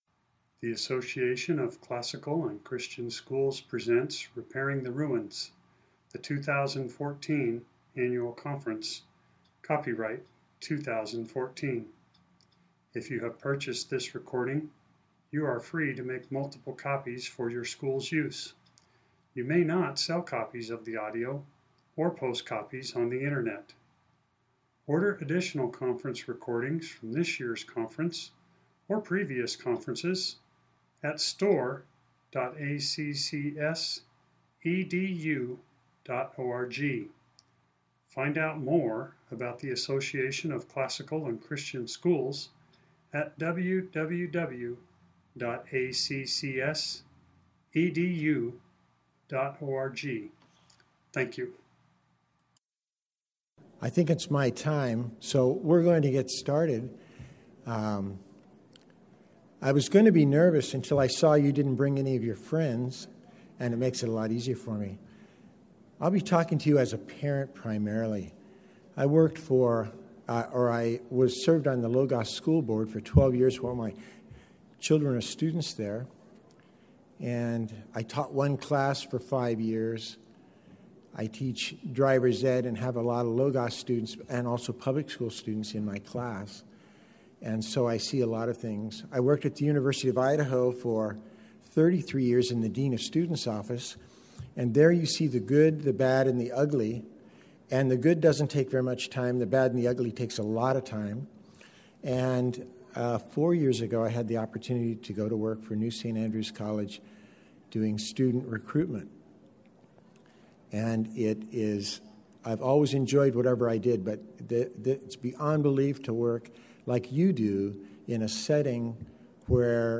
2014 Workshop Talk | 0:54:07 | All Grade Levels, Virtue, Character, Discipline
The Association of Classical & Christian Schools presents Repairing the Ruins, the ACCS annual conference, copyright ACCS.